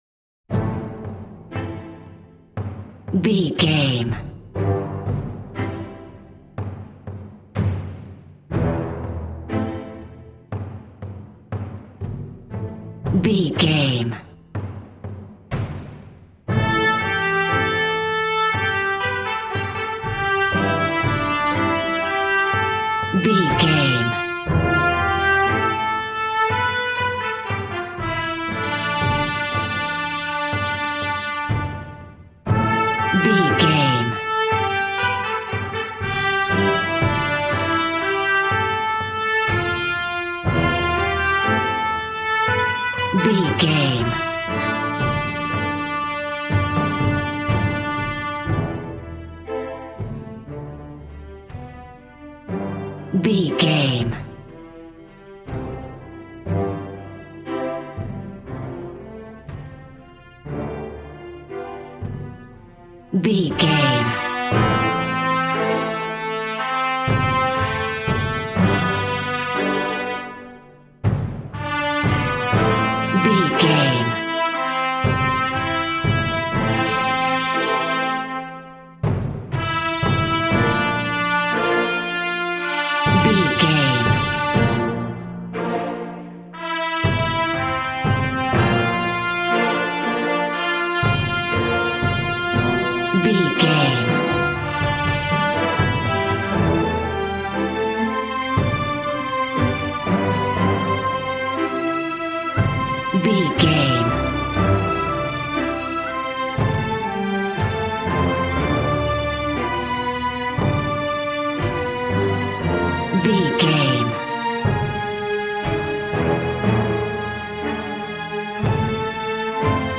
Valiant and Triumphant music for Knights and Vikings.
Regal and romantic, a classy piece of classical music.
Aeolian/Minor
brass
strings
violin
regal